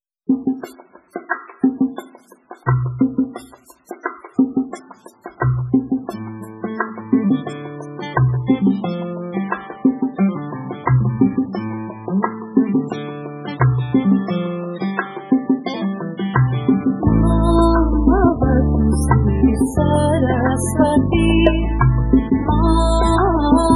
tonal layer